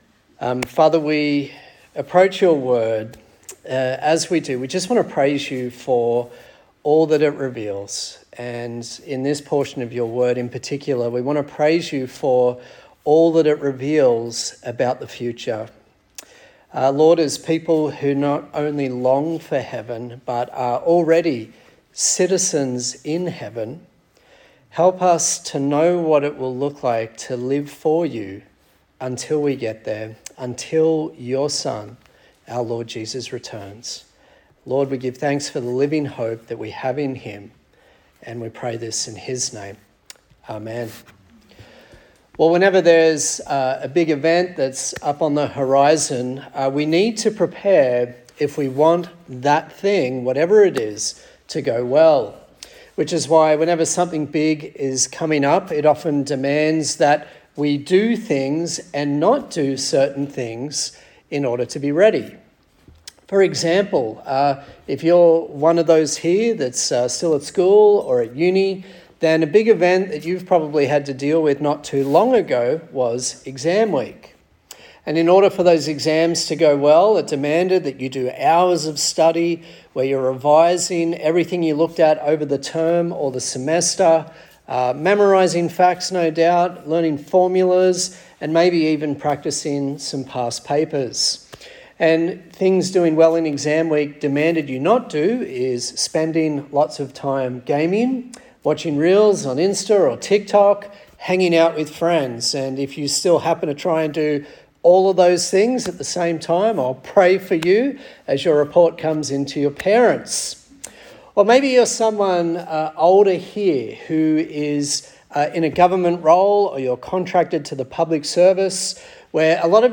James Passage: James 4:13-5:11 Service Type: Sunday Service